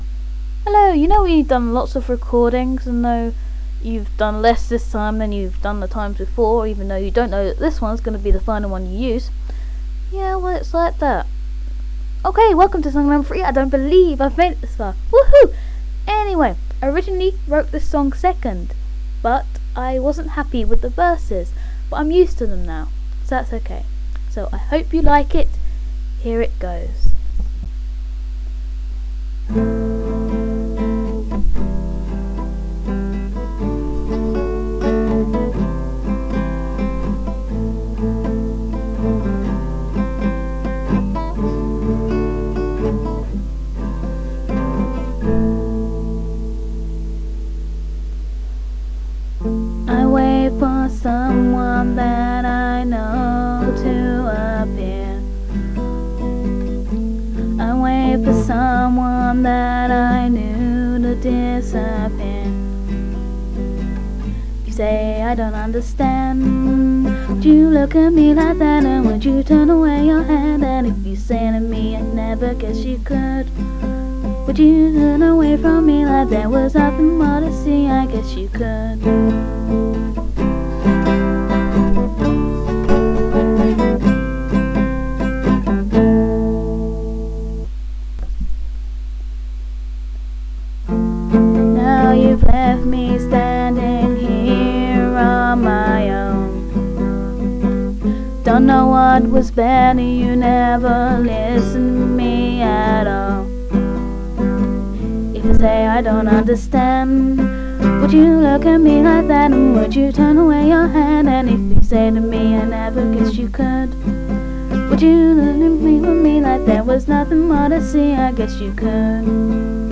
Timings: 1 2 3 & 4 (all up/down pick)
Intro: Am C D C Am